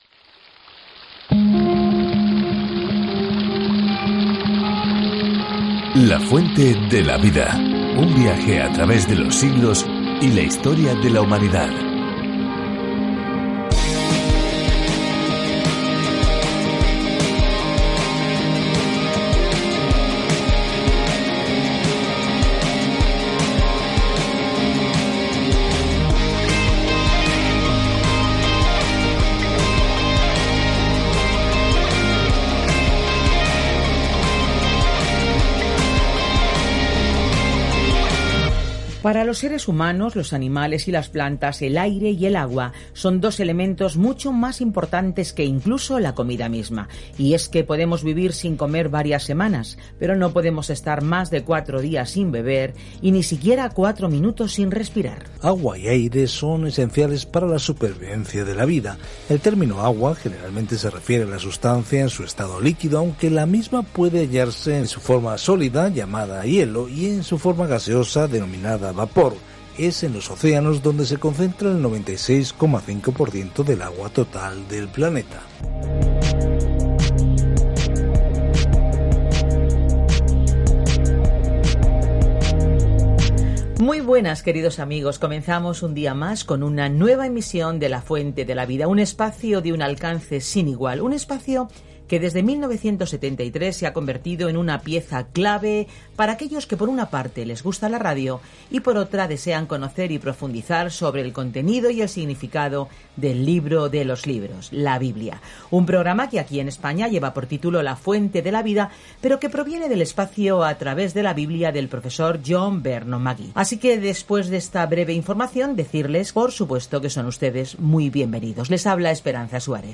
Dios escogió a Jeremías, un hombre de corazón tierno, para entregar un mensaje duro, pero el pueblo no lo recibió bien. Viaja diariamente a través de Jeremías mientras escuchas el estudio en audio y lees versículos seleccionados de la palabra de Dios.